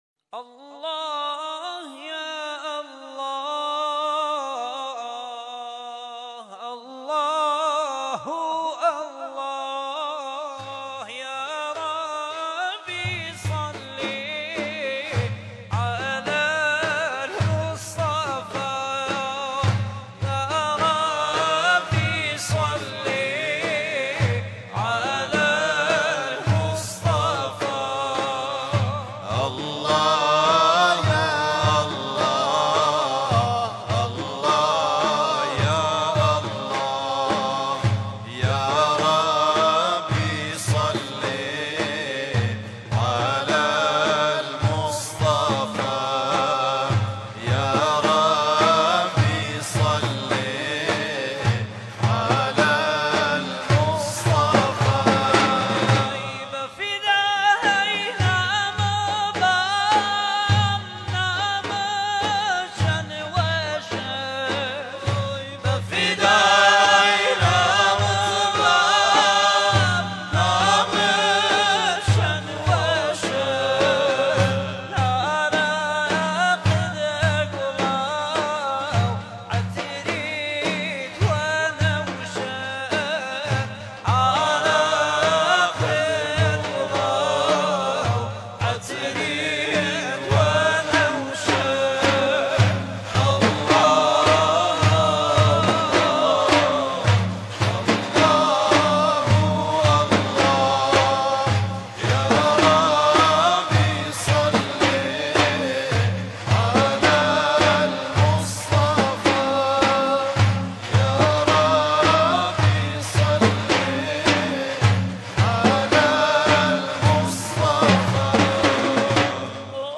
صوت | مداحی کُردی در وصف رسول مهربانی
مدیحه‌سرایی بسیار زیبای کُردی
به‌مناسبت هفته وحدت
مداحی